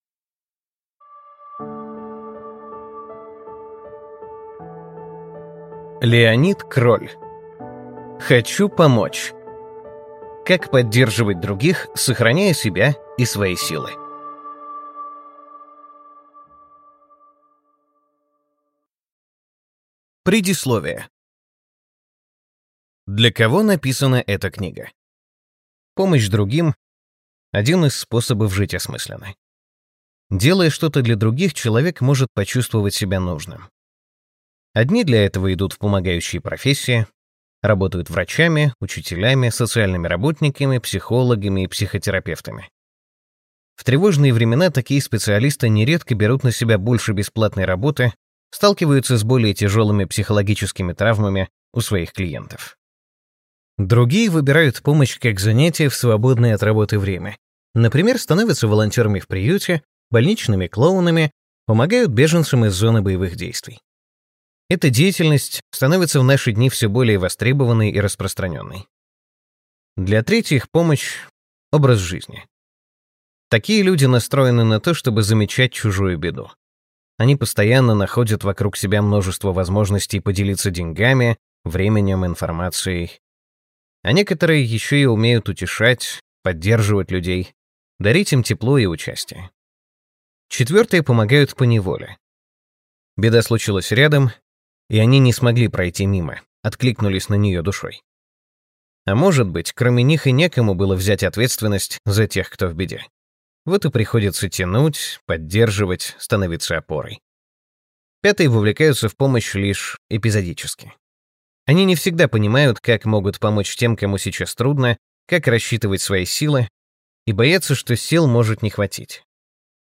Аудиокнига Хочу помочь. Как поддерживать других, сохраняя себя и свои силы | Библиотека аудиокниг